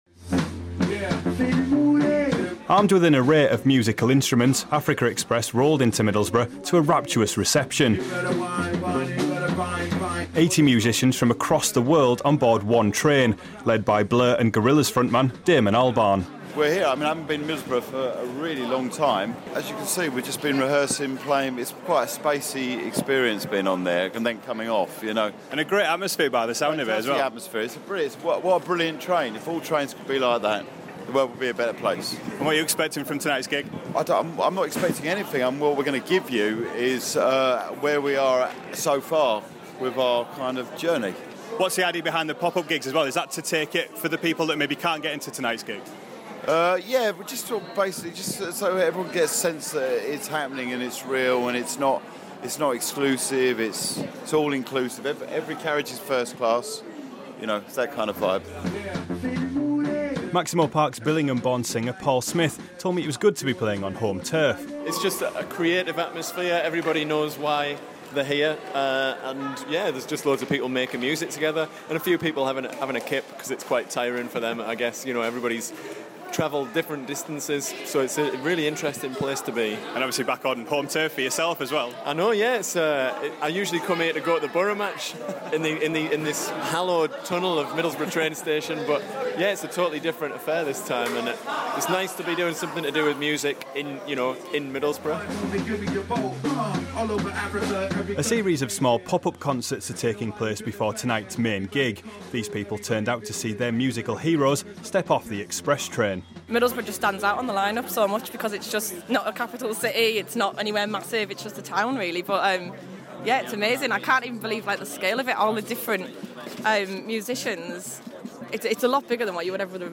Damon Albarn, Paul Smith and Carl Barat talk ahead of their Africa Express gig in Middlesbrough.